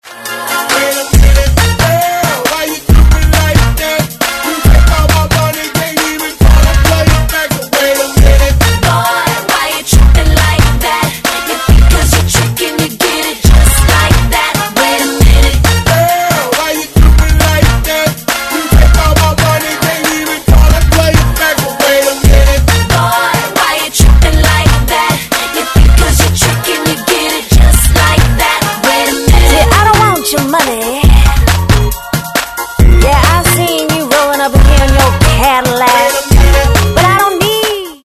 Припев этой песни.
Таги: mp3, RnB, РЅР°СЂРµР·РєР°, СЂРёРЅРіС‚РѕРЅ